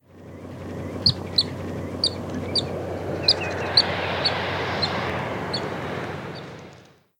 Little Stint
Calidris minuta